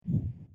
flap.wav